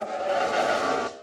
骷髅马：嘶叫
骷髅马马在在陆地上空闲时
Minecraft_skeleton_horse_idle1.mp3